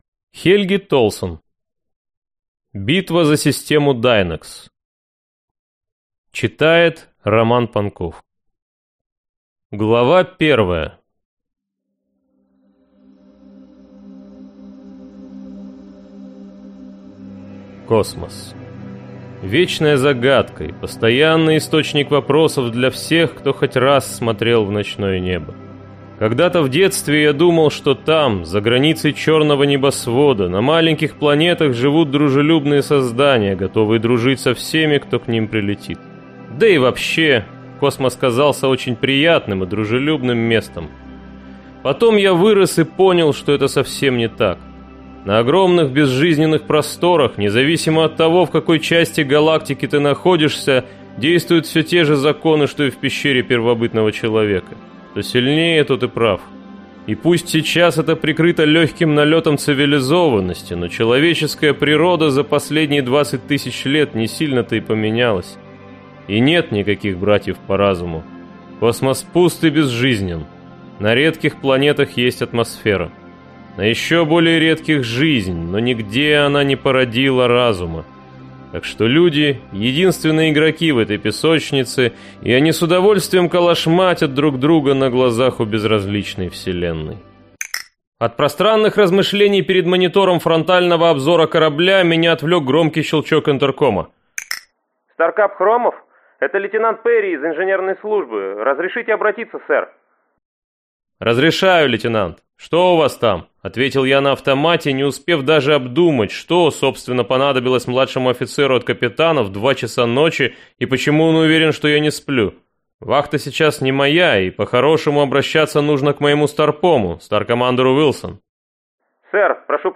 Аудиокнига Битва за систему Дайнекс | Библиотека аудиокниг